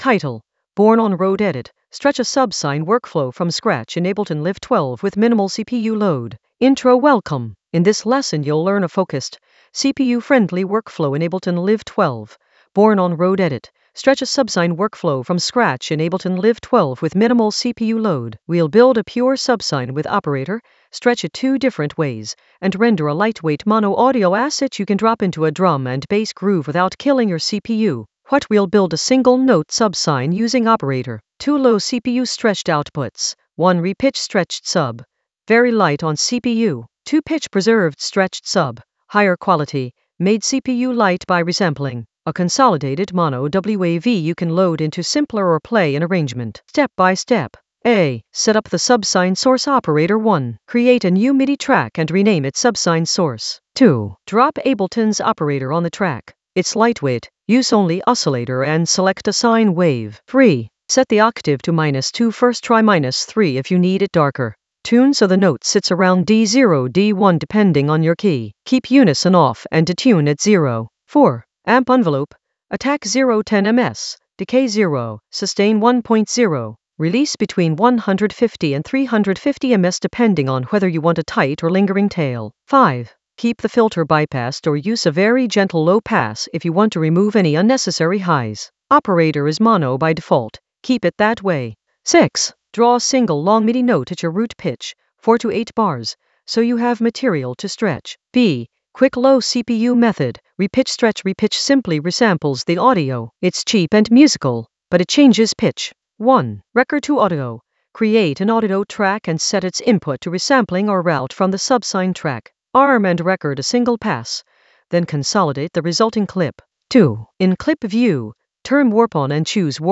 An AI-generated beginner Ableton lesson focused on Born on Road edit: stretch a subsine workflow from scratch in Ableton Live 12 with minimal CPU load in the Groove area of drum and bass production.
Narrated lesson audio
The voice track includes the tutorial plus extra teacher commentary.